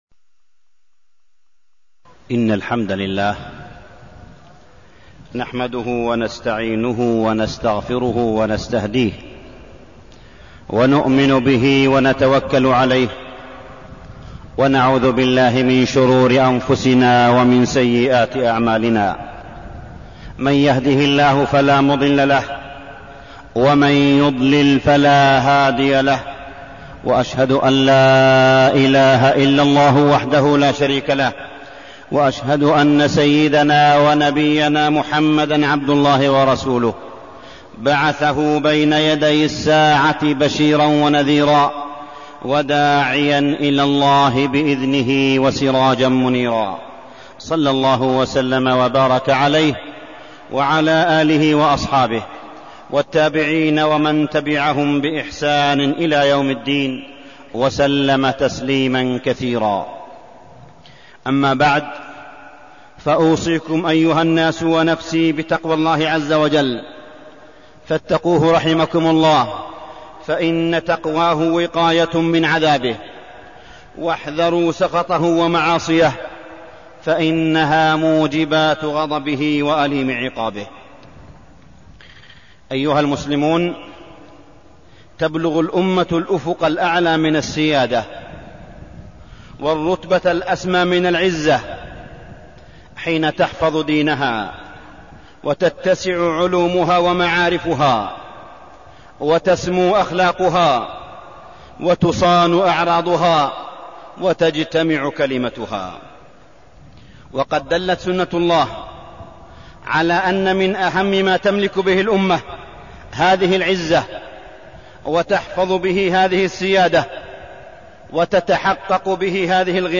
تاريخ النشر ١١ ربيع الأول ١٤١٧ هـ المكان: المسجد الحرام الشيخ: معالي الشيخ أ.د. صالح بن عبدالله بن حميد معالي الشيخ أ.د. صالح بن عبدالله بن حميد الإقتصاد وحسن الإنفاق